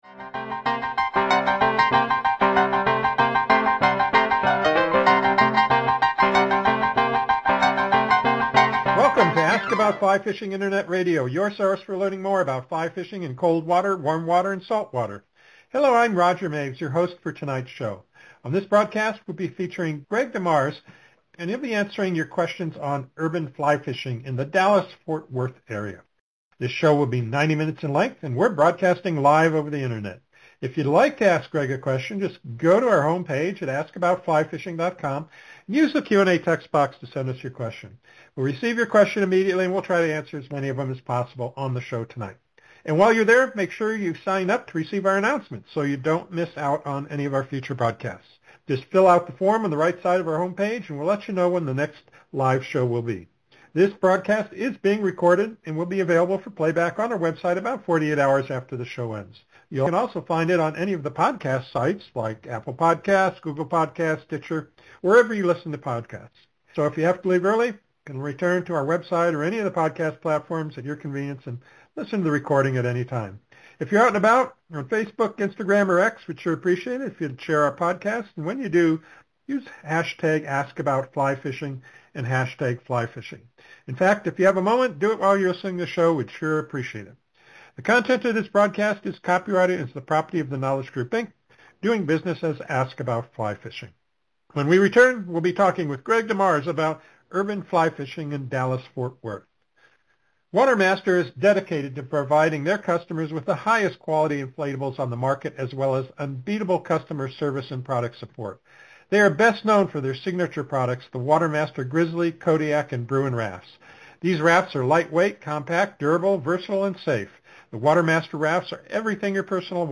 Just a few of the questions asked and answered during the interview: